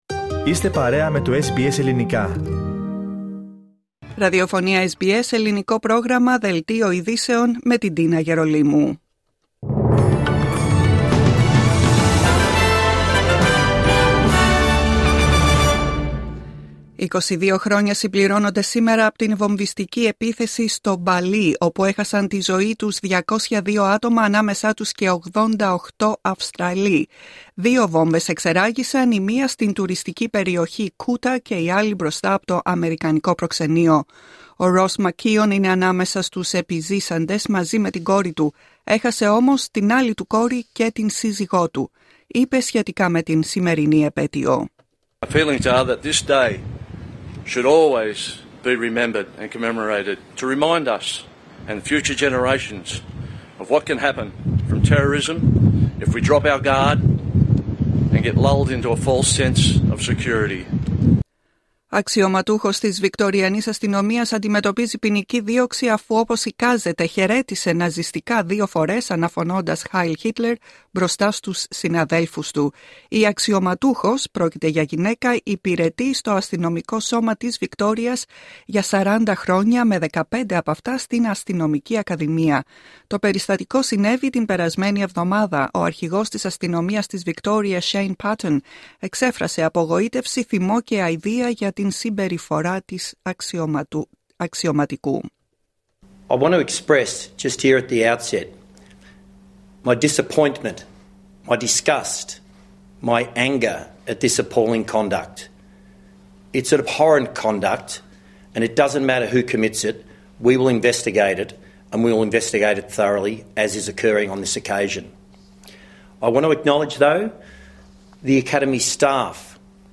Δελτίο ειδήσεων απ΄το Ελληνικό Πρόγραμμα της SBS.